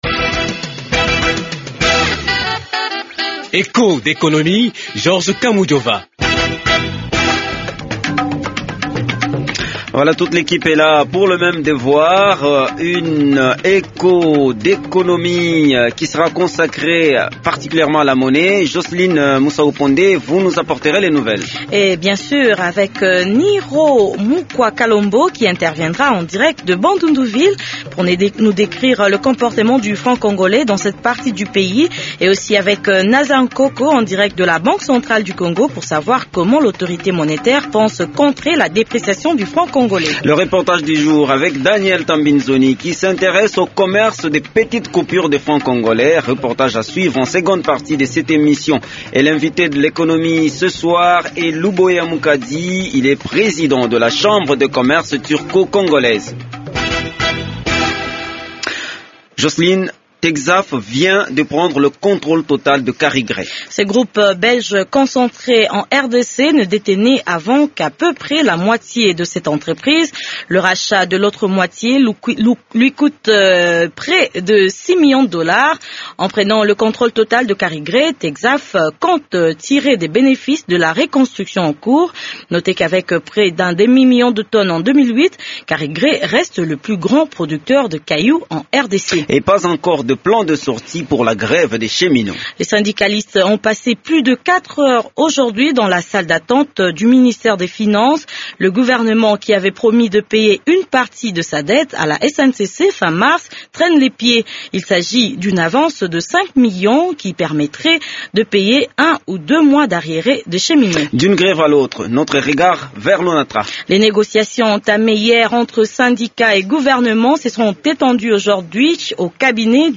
Depuis le debut de l’année, le franc congolais s’est dépréciée d’au moins 30%. Echos d’économie voyage au Bandundu pour voir les conséquences de cette situation.
Des petites coupures se vendent contre les grosses. C’est le reportage que vous propose Echos d’Economie.